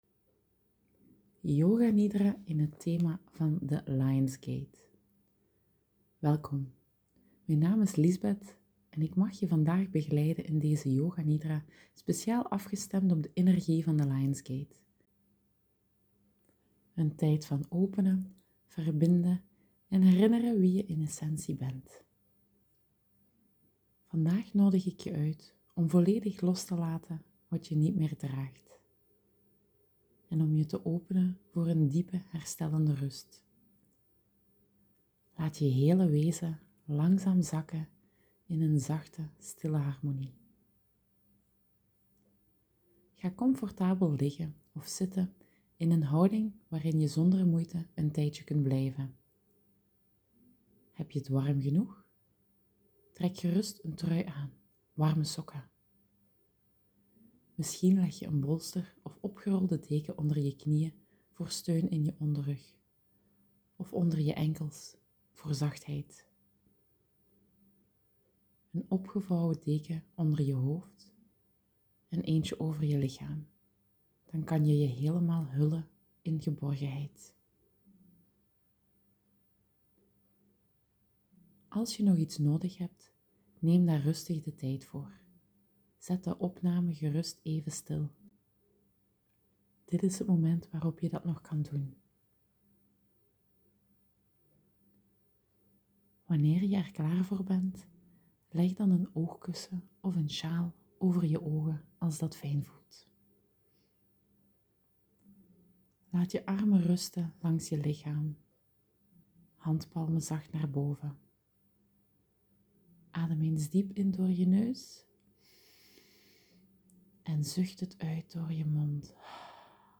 Yoga Nidra is een vorm van begeleide meditatie waarbij je lichaam en zenuwstelsel volledig tot rust komen.